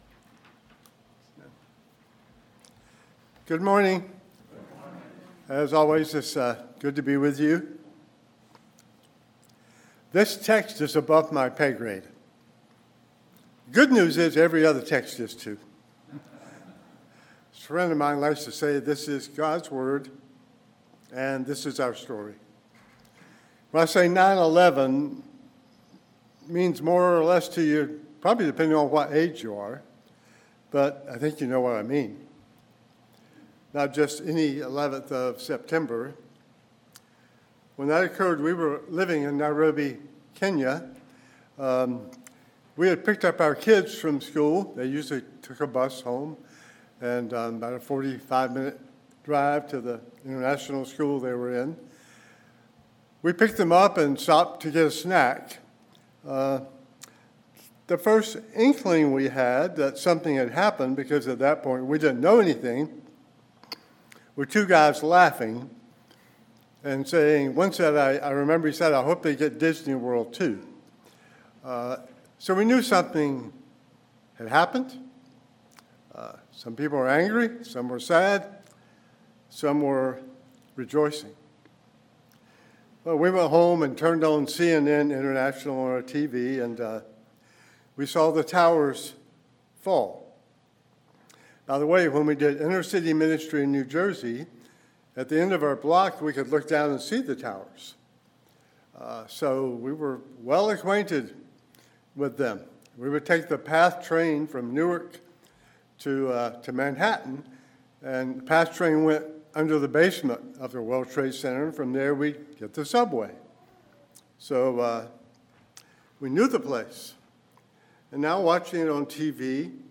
Message on the lament and grief of Psalm 137 which points to the redemption of God’s people in Christ.